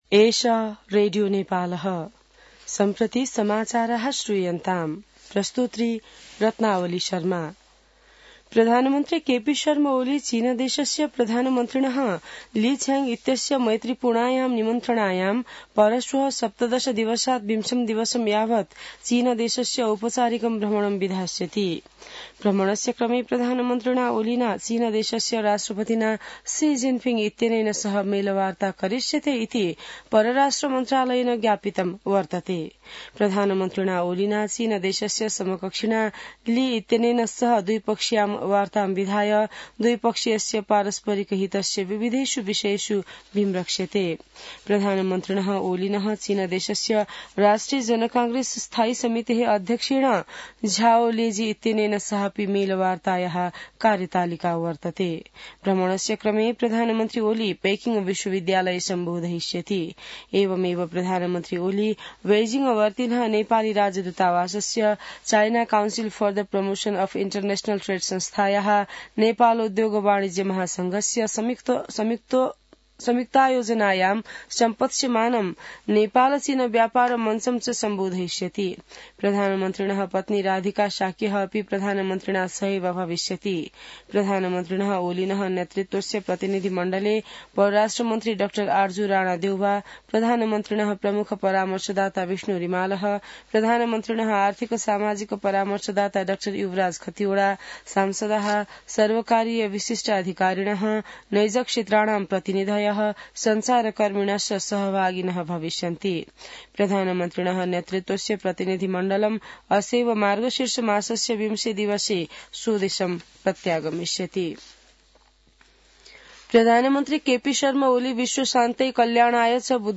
संस्कृत समाचार : १६ मंसिर , २०८१